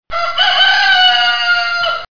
Rooster